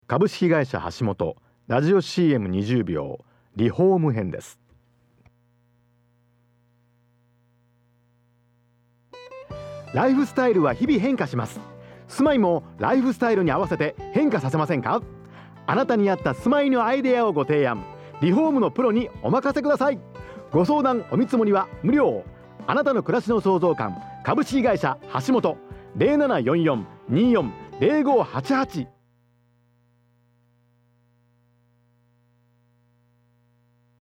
株式会社はしもと R-CM リホーム 2018年02月22日制作